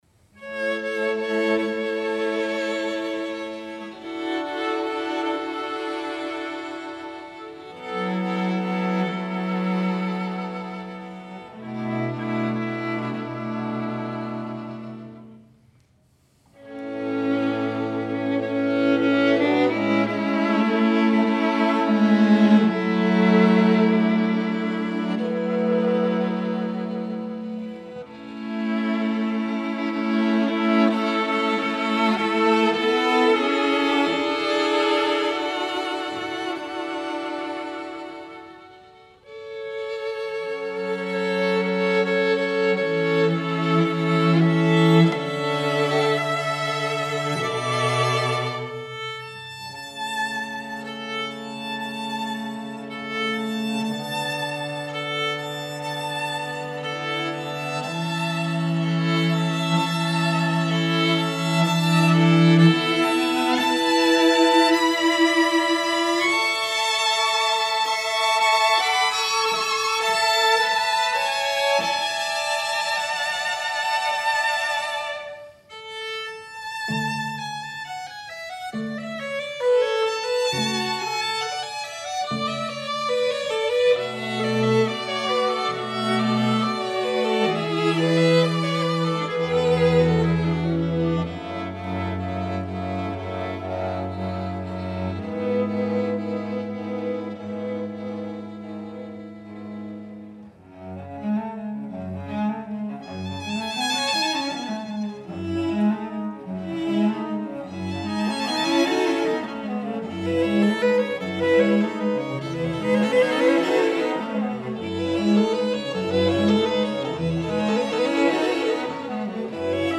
for String Quartet (2014)
The ending is once again serene.